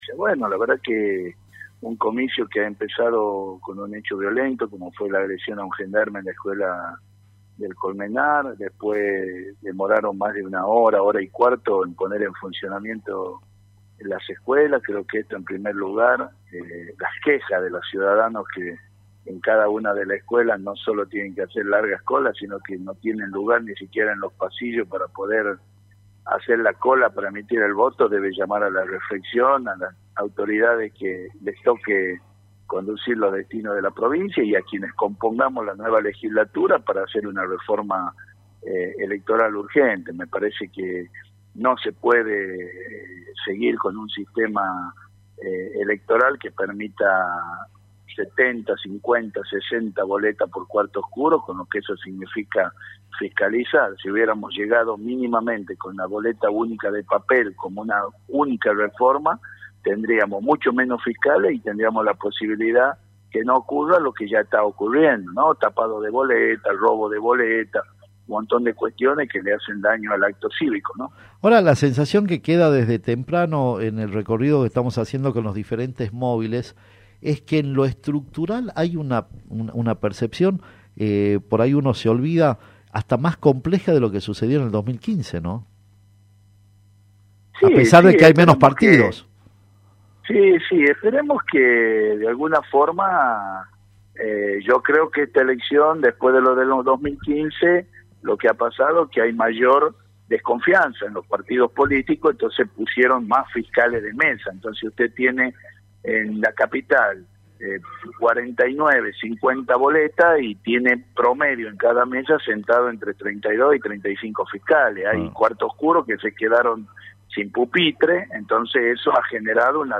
Luego de emitir su voto, el candidato a Legislador capitalino habló en Radio Q.